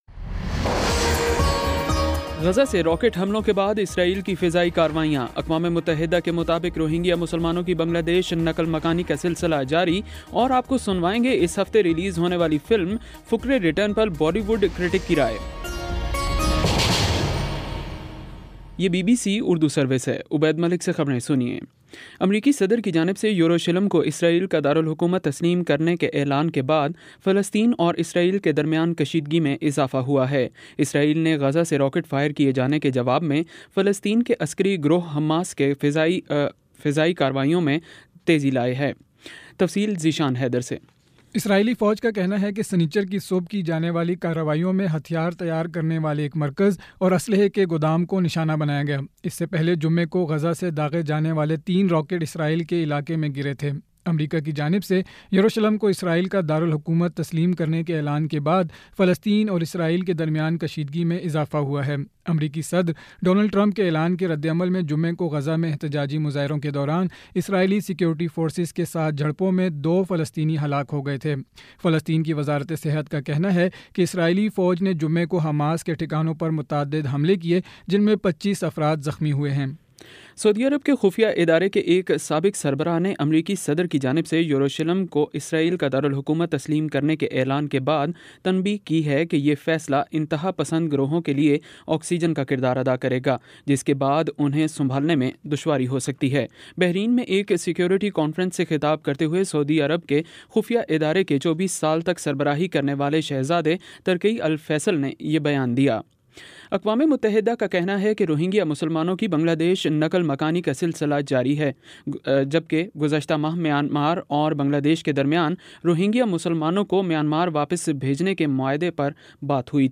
دسمبر 09 : شام چھ بجے کا نیوز بُلیٹن